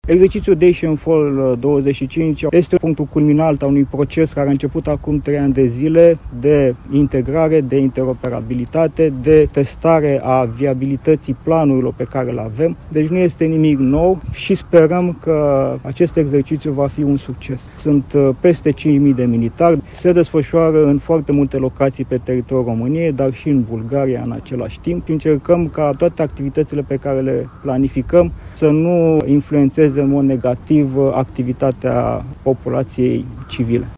El marchează și etapa finală de ridicare la nivel brigadă a grupurilor de luptă NATO din cele două ţări, după cum a precizat generalul Dorin Toma, comandantul acestei structuri aliate: